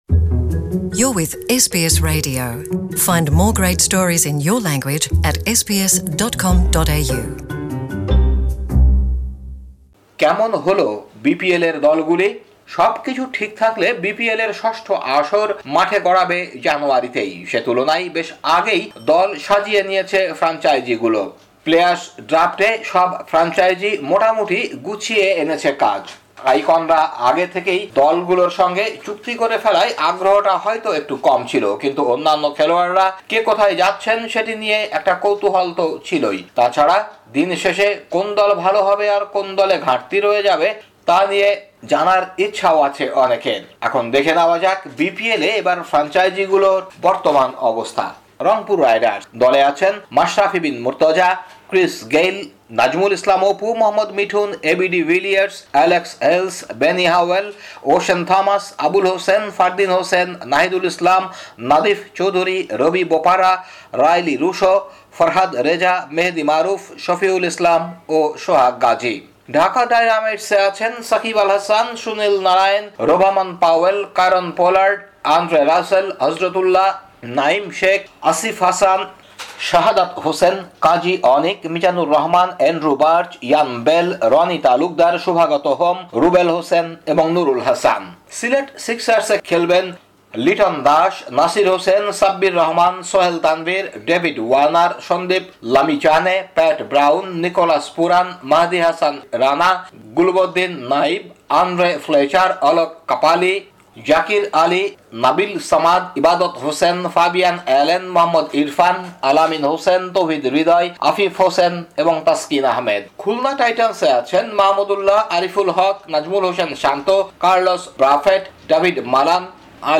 বিপিএলে এবার ফ্রাঞ্চাইজিগুলোর বর্তমান অবস্থা নিয়ে ঢাকা থেকে রিপোর্ট করছেন